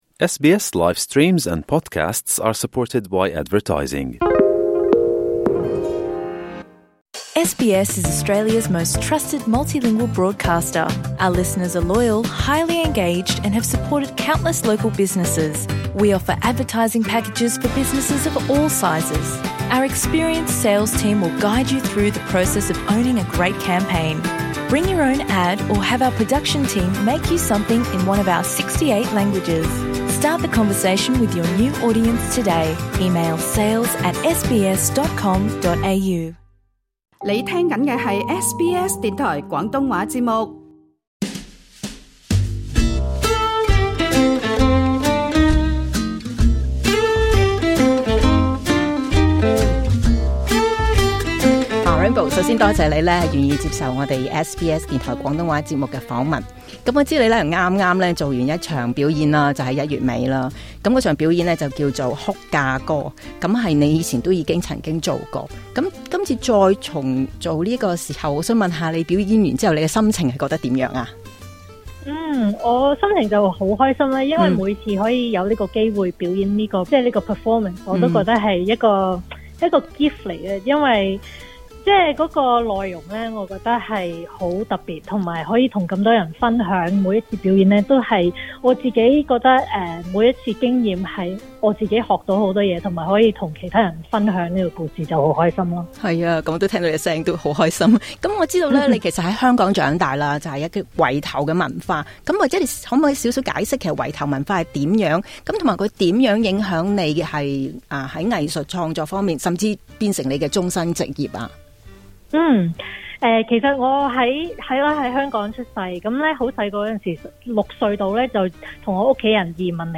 今集【光影會客室】為她做了一節訪談，聽她活躍的光影世界。